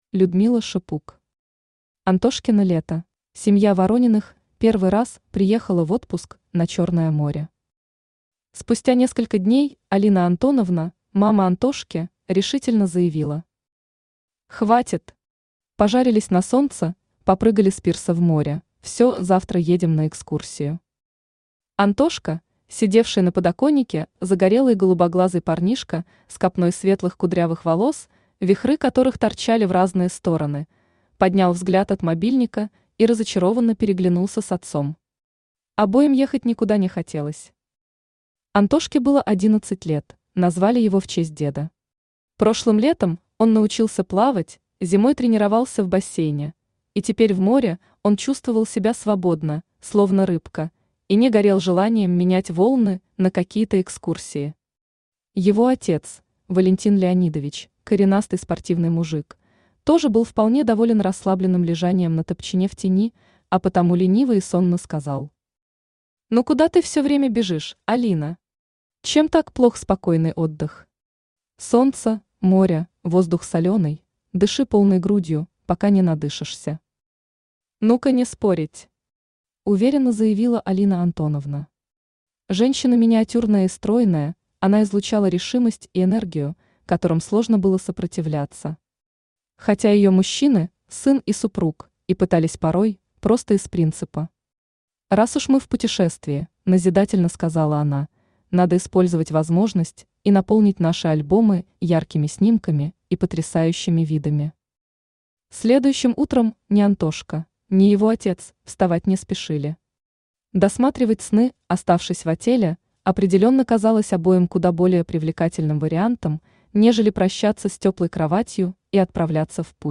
Аудиокнига Антошкино лето | Библиотека аудиокниг
Aудиокнига Антошкино лето Автор Людмила Шипук Читает аудиокнигу Авточтец ЛитРес.